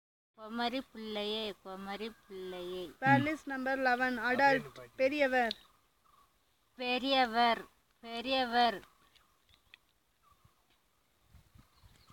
NotesThis is an elicitation of words about stages of life, using the SPPEL Language Documentation Handbook.